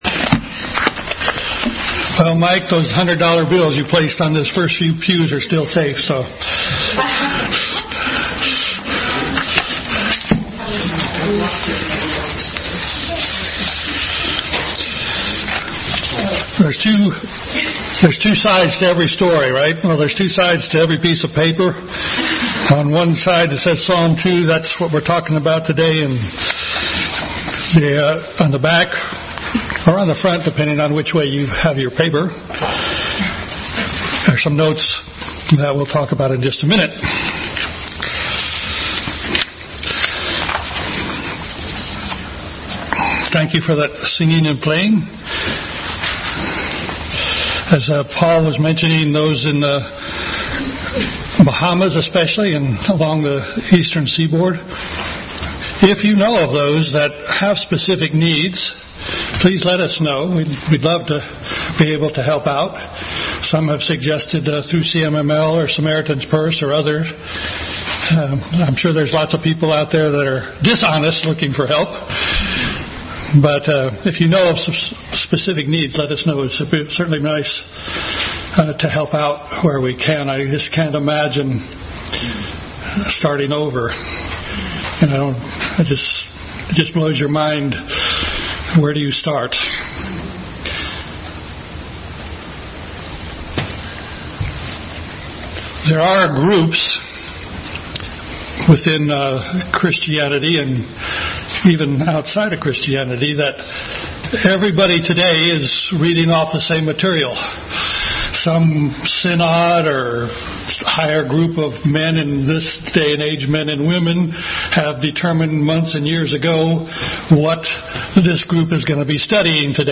Bible Text: Psalm 2 | Family Bible Hour message.